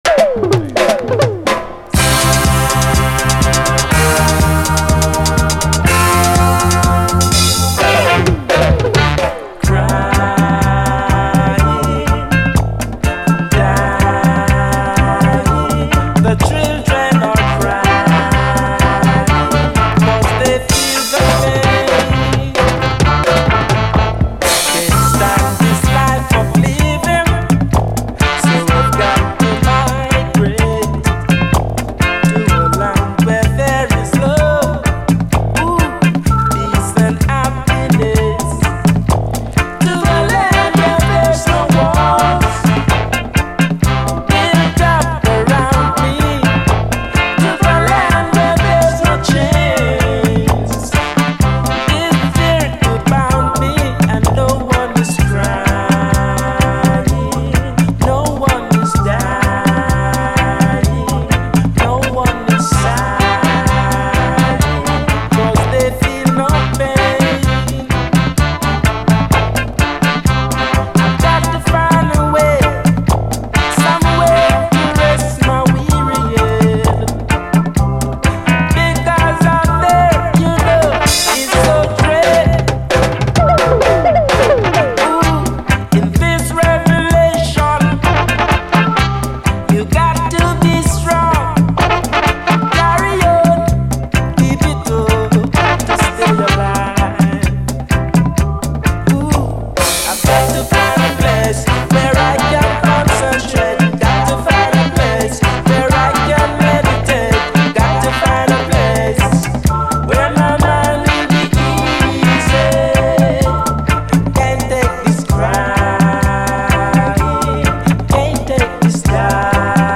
REGGAE
ゴキゲンなステッパー・ルーツ２曲！
フワフワと温もりあるコーラス＆メロディー、ファットなブラスが楽しい一曲！
やはりコーラス・ワークが綺麗です。
どちらも後半はダブ。